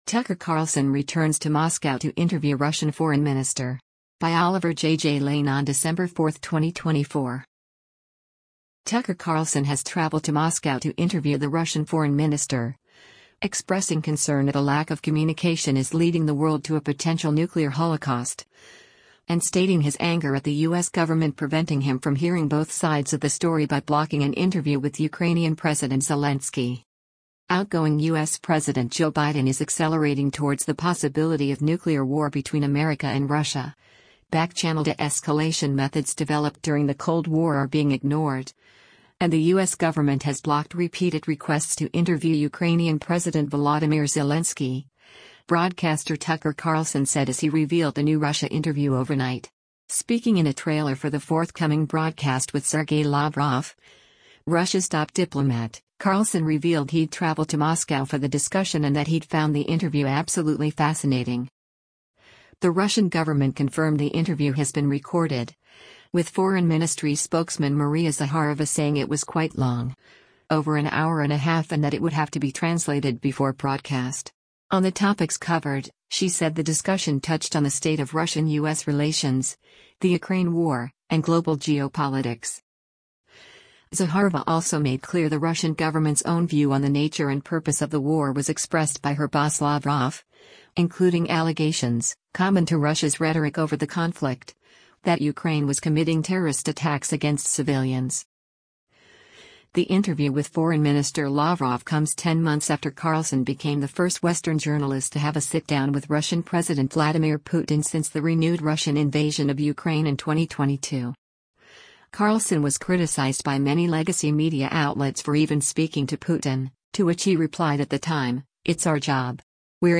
Speaking in a trailer for the forthcoming broadcast with Sergei Lavrov, Russia’s top diplomat, Carlson revealed he’d travelled to Moscow for the discussion and that he’d found the interview “absolutely fascinating”.